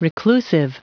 Prononciation du mot : reclusive